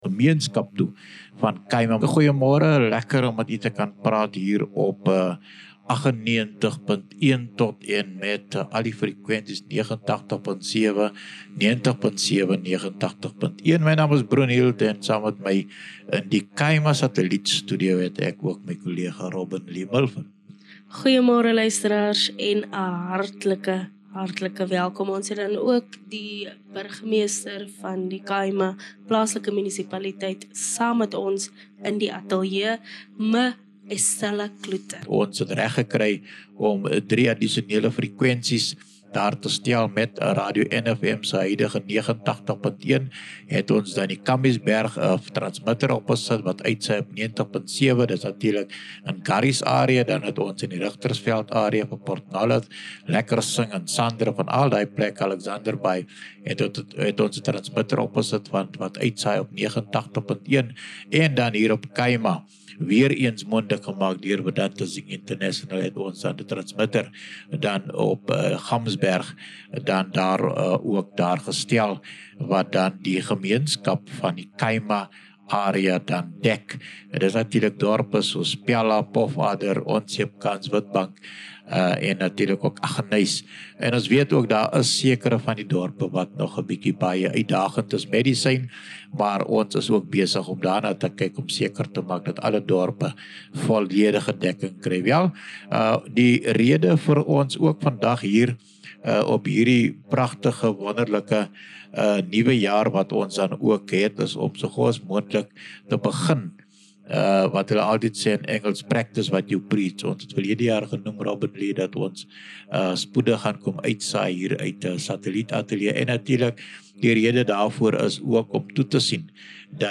In gesprek met die Burgemeester van die Khai-Ma Plaaslike Munisipaliteit, raadslid Estella Cloete, ontleed ons wat hierdie uitbreiding beteken vir gemeenskapsverteenwoordiging, deursigtigheid in plaaslike regering en die versterking van gemeenskapsstemme, terwyl ons ook vrae ondersoek rondom mediainvloed, aanspreeklikheid en die verwagtinge wat aan beide die radiostasie en munisipale leierskap gestel word.